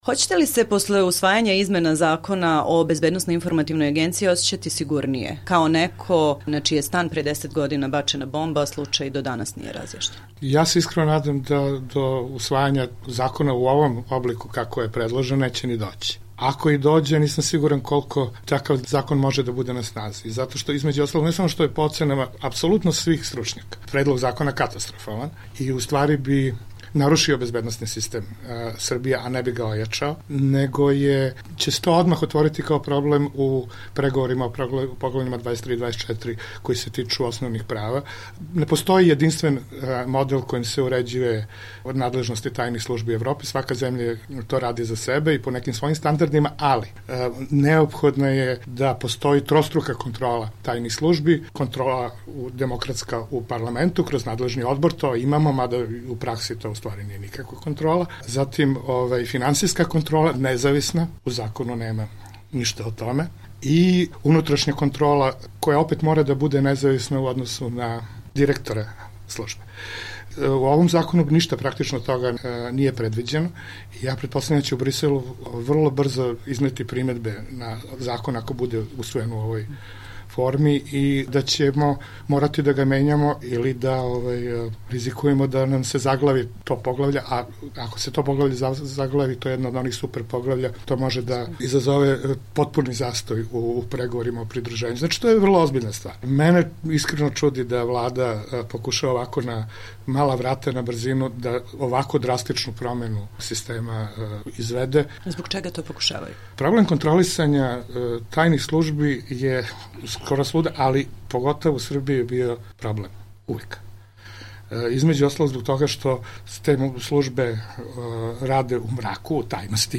Intervju nedelje